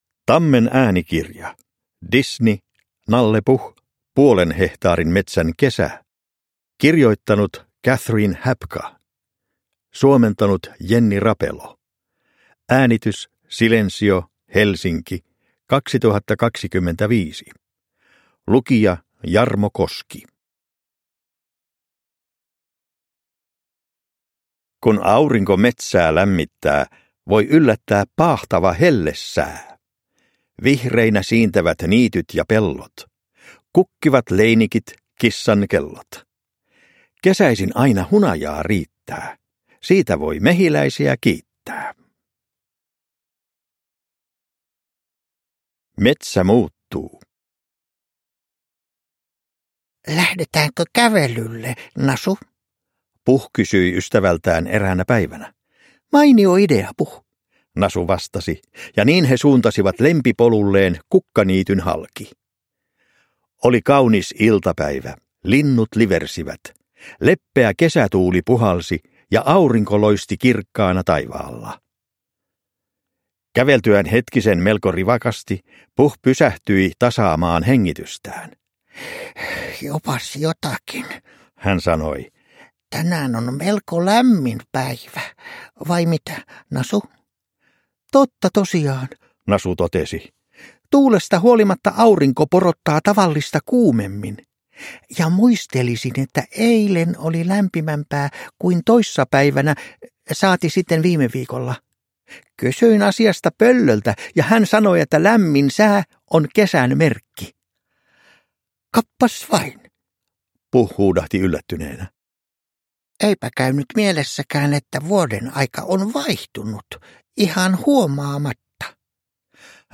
Disney. Nalle Puh. Puolen hehtaarin metsän kesä – Ljudbok